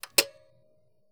button_up.wav